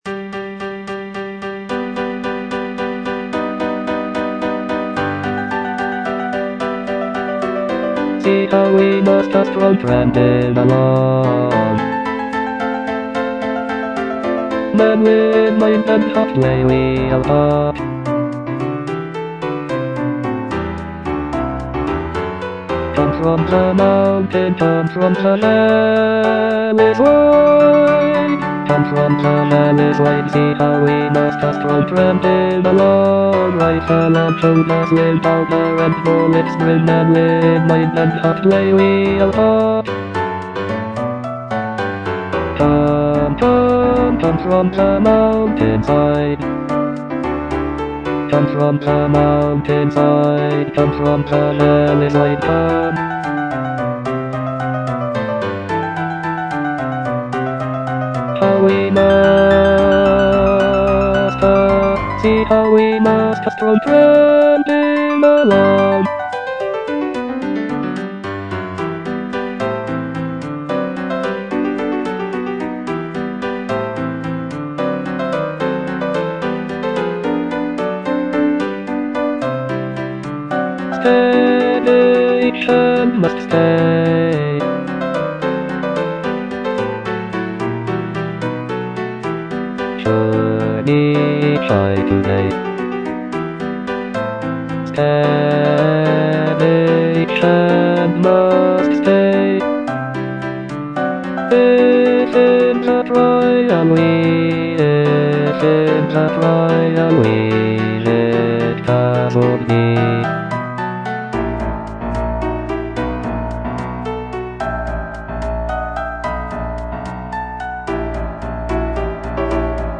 bass II) (Voice with metronome